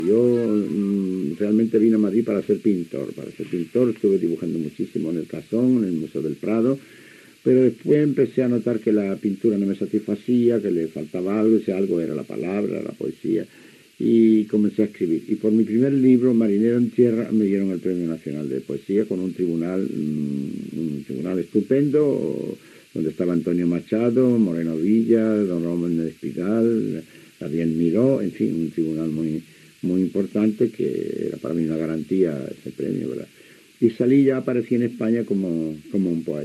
El poeta Rafael Alberti recorda els seus inicis i el primer premi que va rebre.